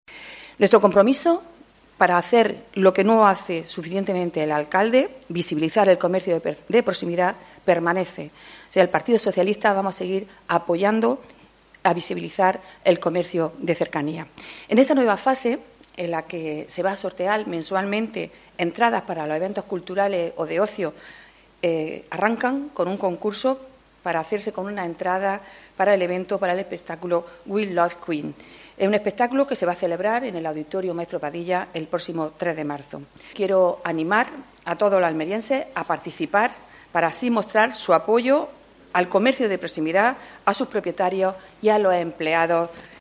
Adriana Valverde, portavoz del PSOE en el Ayuntamiento de Almería